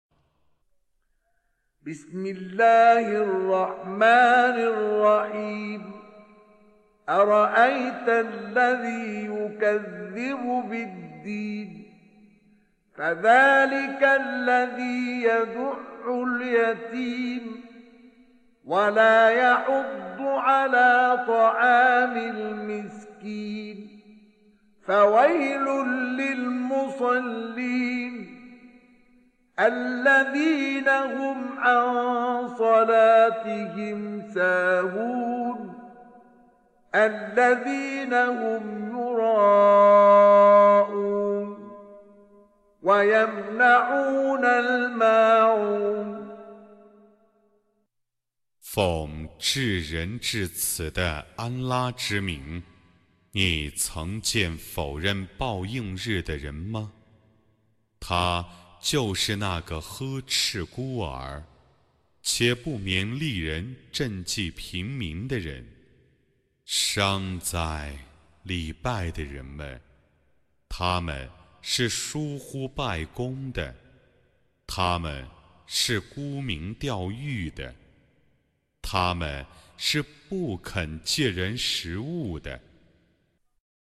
Reciting Mutarjamah Translation Audio for 107. Surah Al-Mâ'ûn سورة الماعون N.B *Surah Includes Al-Basmalah